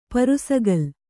♪ parusagal